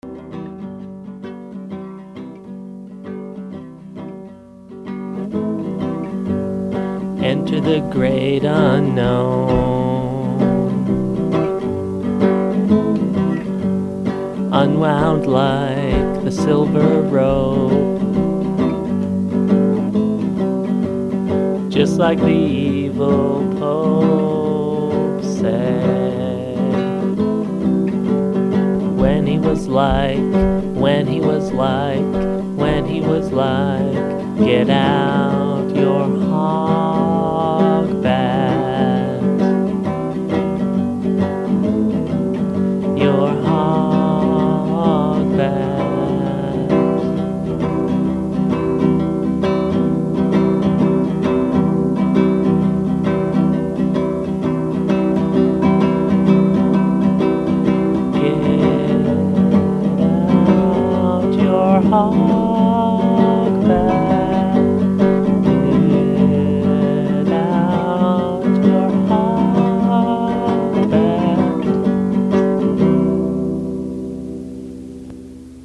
acoustic demo